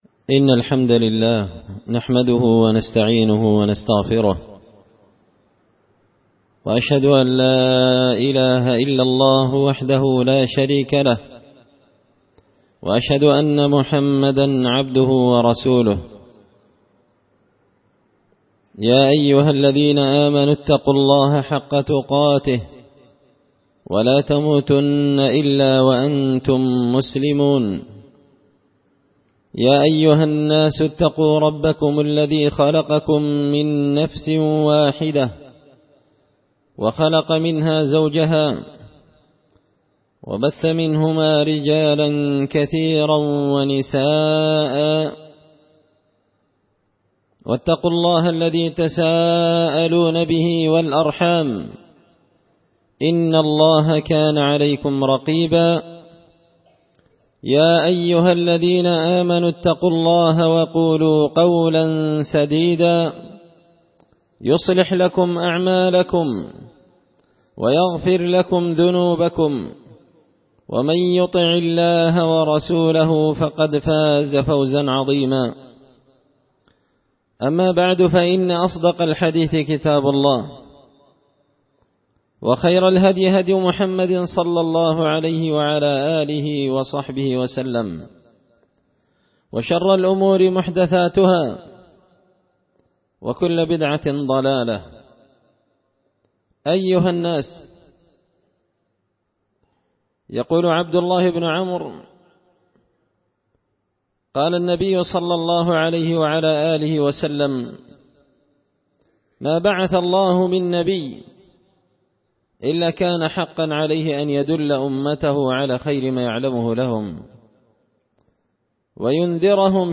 خطبة جمعة بعنوان – تذكير أولي النهى بأمور حذر النبي من تركها
دار الحديث بمسجد الفرقان ـ قشن ـ المهرة ـ اليمن